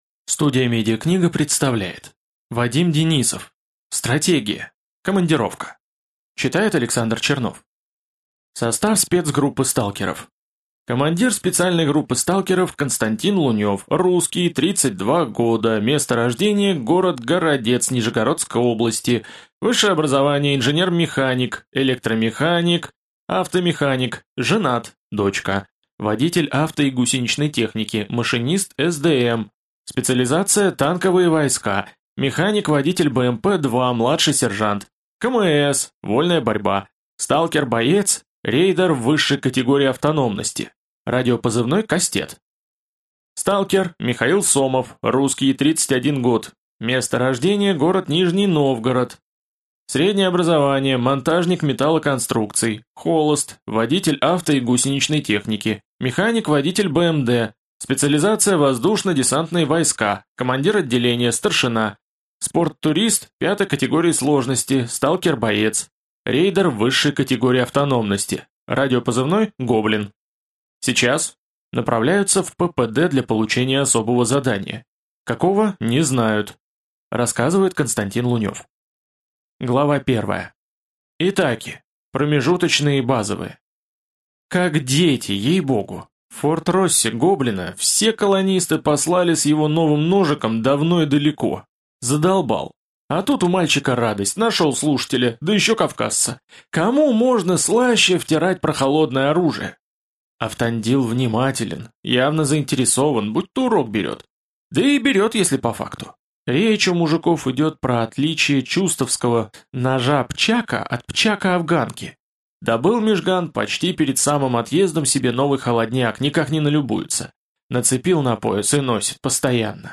Аудиокнига Стратегия. Командировка | Библиотека аудиокниг